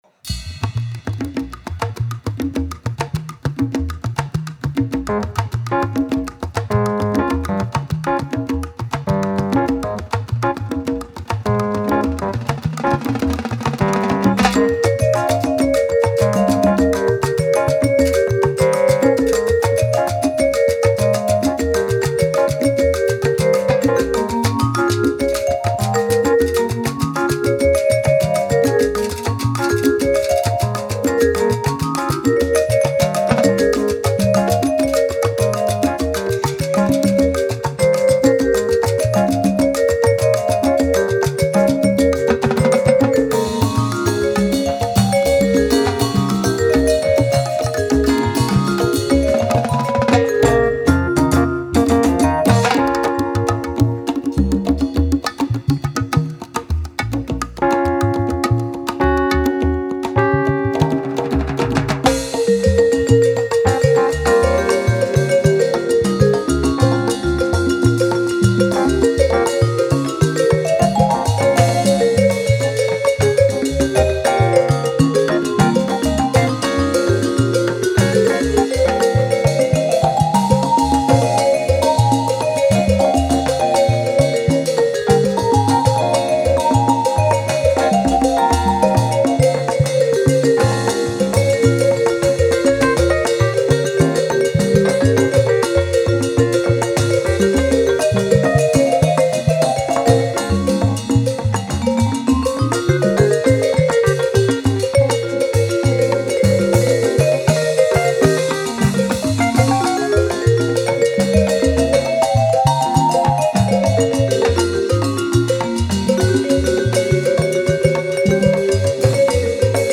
Live recordings
lead vocals
piano/keyboards, vocals
vibraphone
bass
bongo/campana, vocals